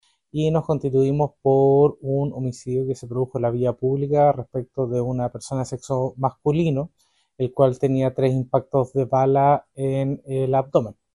fiscal.mp3